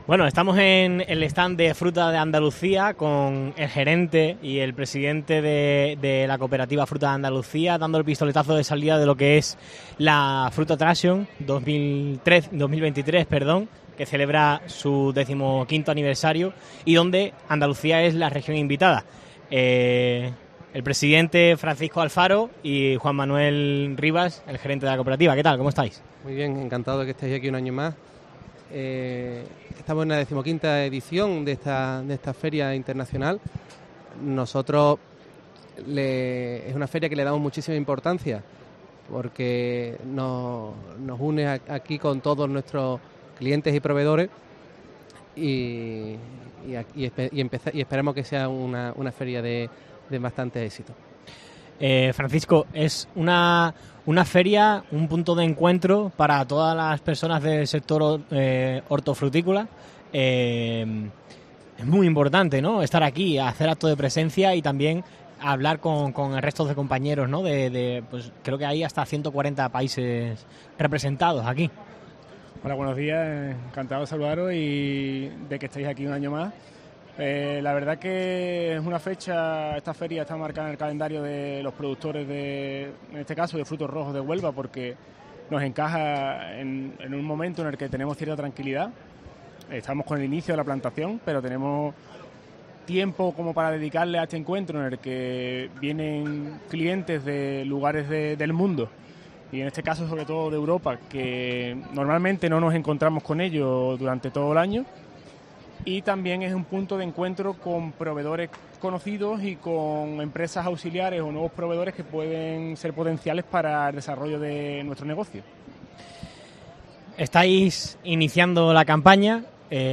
Fruit Attraction 2023 | Entrevista